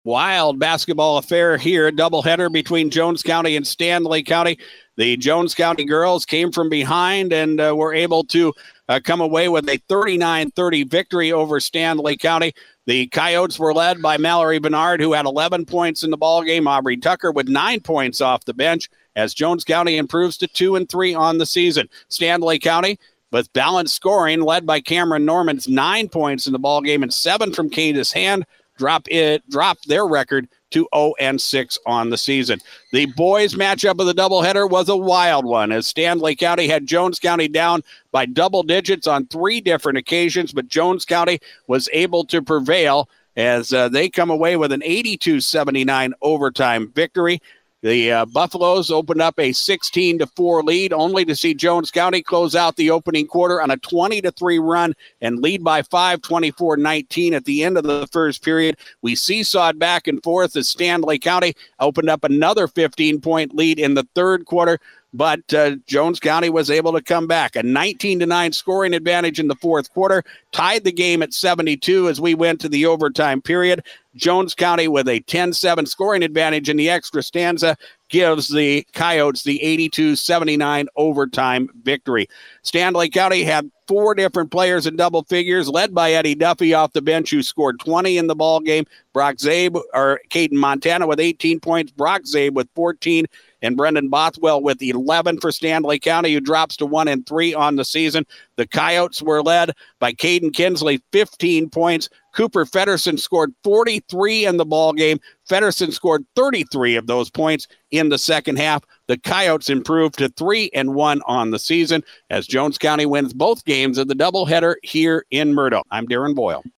DRG sportscaster